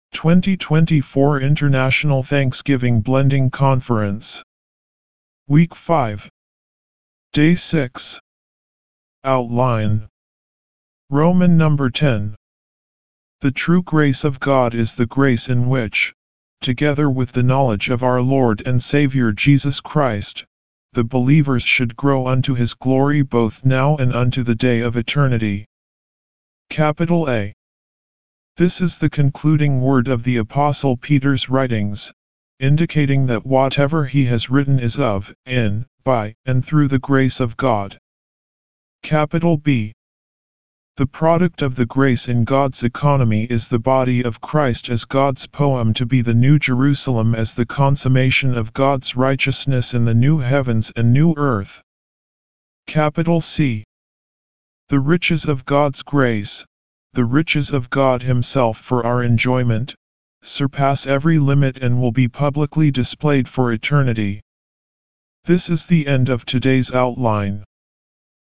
D6 English Rcite：